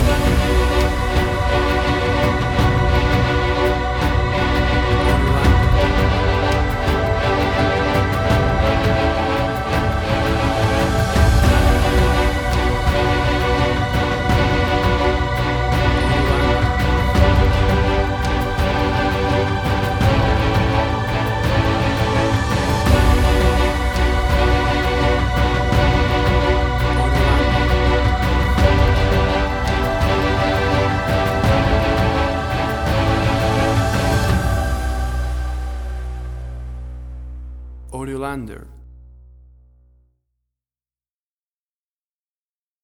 WAV Sample Rate: 16-Bit stereo, 44.1 kHz
Tempo (BPM): 85